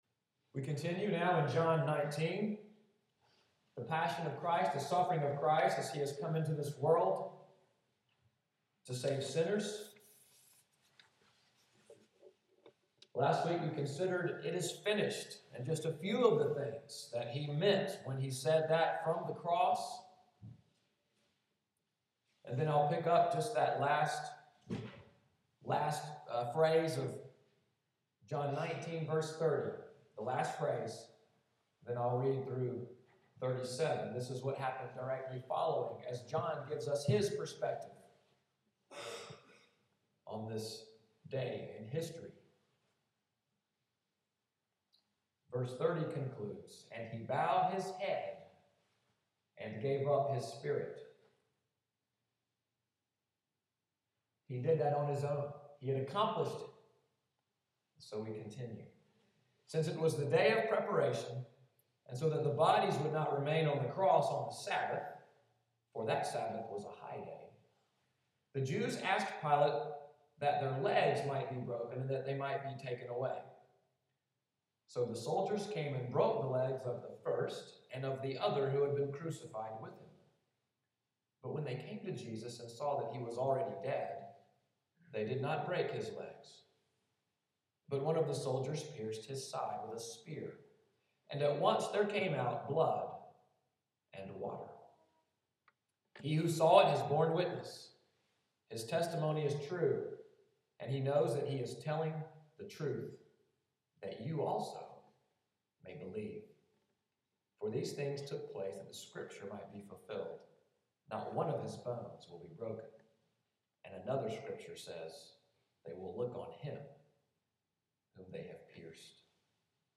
Audio from the sermon, “Blood and Water Flowed.” April 6, 2014.